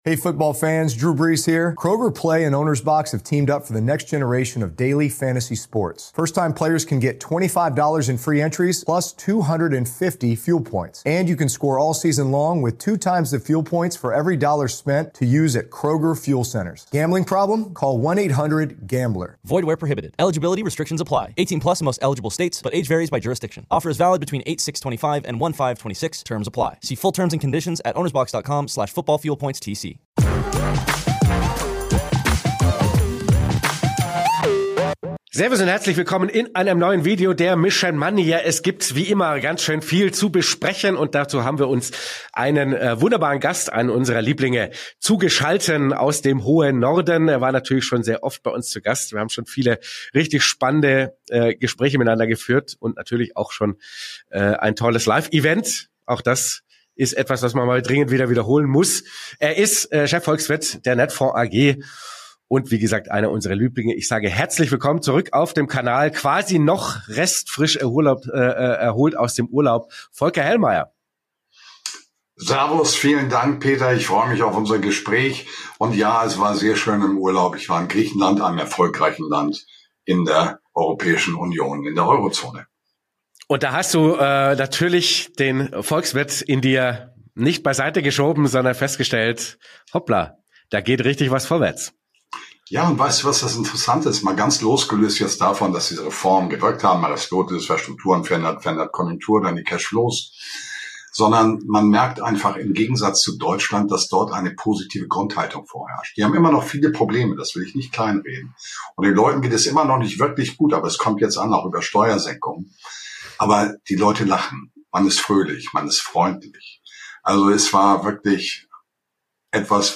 Im Interview fordert er weniger Bürokratie, mehr Pragmatismus und ein investitionsfreundliches Klima.